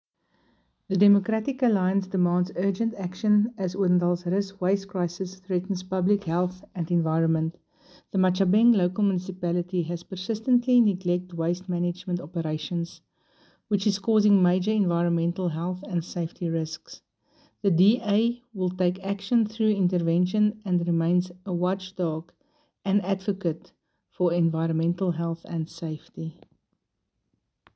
Afrikaans soundbites by Cllr Jessica Nel and Sesotho soundbite by Jafta Mokoena MPL.